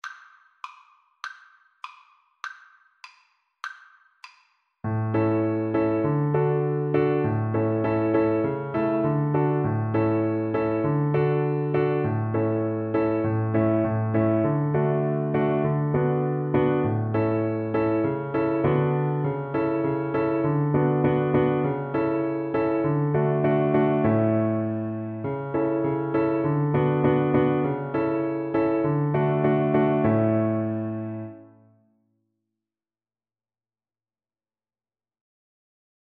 Violin
2/4 (View more 2/4 Music)
Moderato
D major (Sounding Pitch) (View more D major Music for Violin )
Traditional (View more Traditional Violin Music)
Chinese